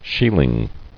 [shiel·ing]